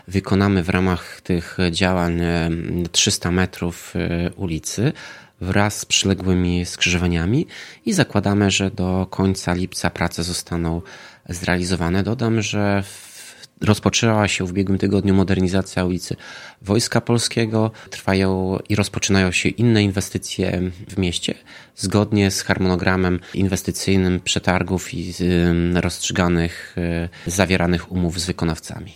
– Prace na osiedlu Zatorze już ruszyły – informuje Tomasz Andrukiewicz, prezydent Ełku.